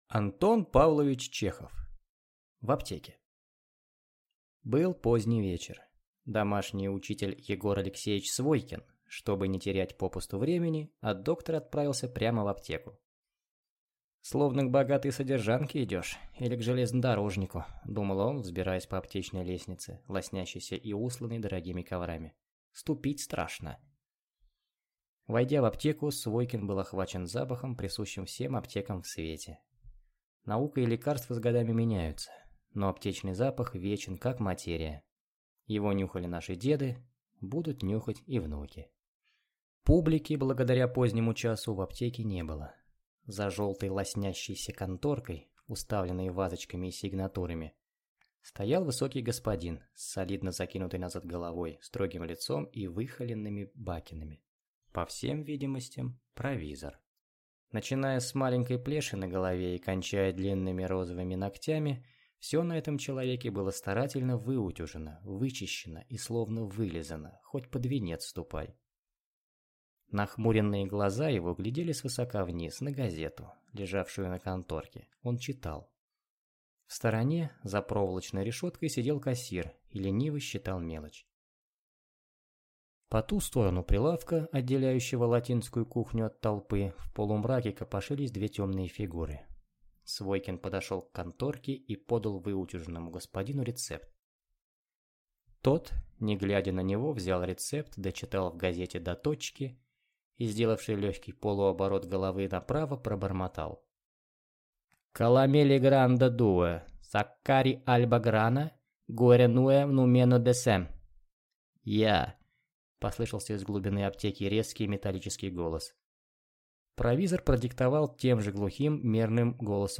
Аудиокнига В аптеке | Библиотека аудиокниг